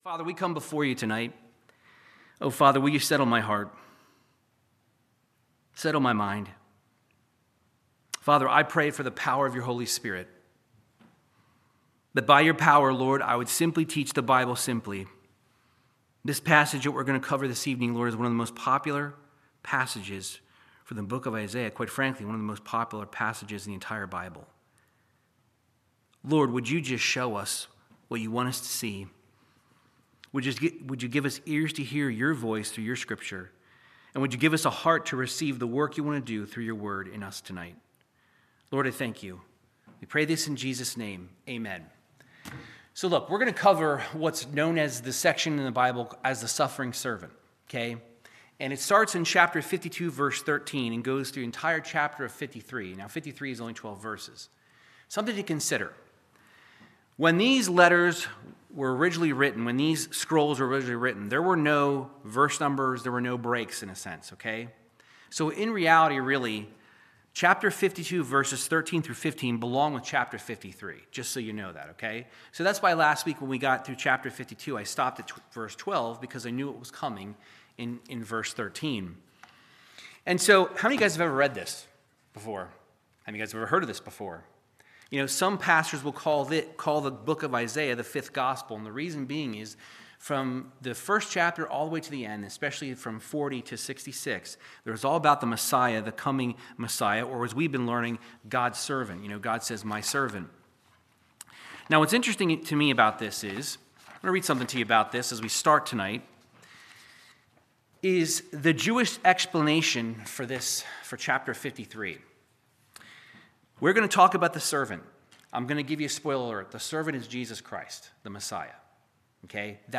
Verse by verse Bible teaching in Isaiah 53